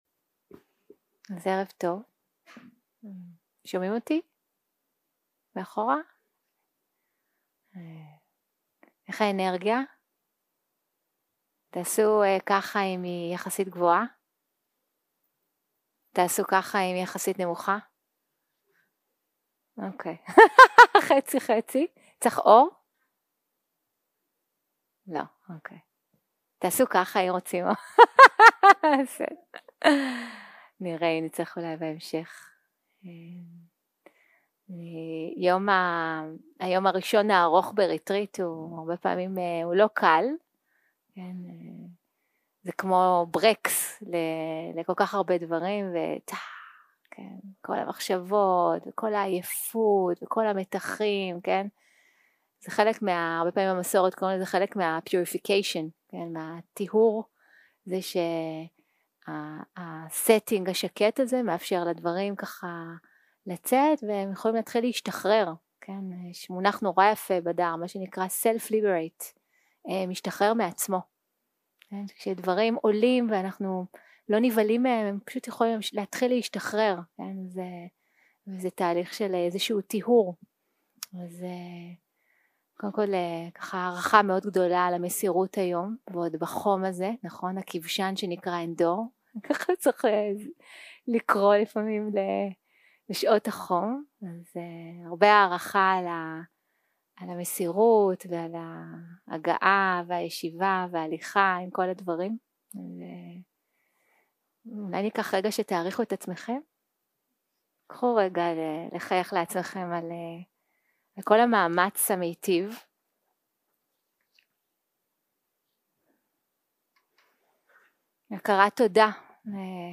יום 2 - הקלטה 4 - ערב - שיחת דהרמה - תשומת לב לתגובות ומשל החיצים 0:00 0:00 סוג ההקלטה: Dharma type: Dharma Talks שפת ההקלטה: Dharma talk language: Hebrew